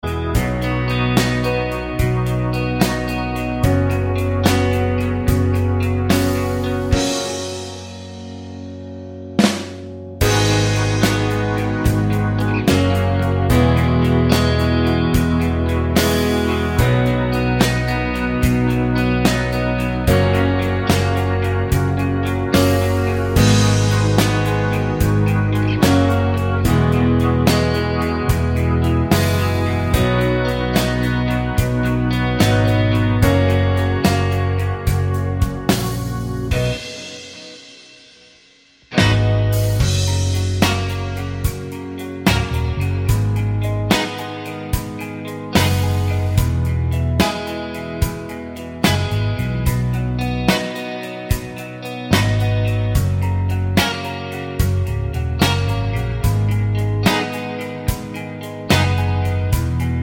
for solo female Pop (2010s) 3:54 Buy £1.50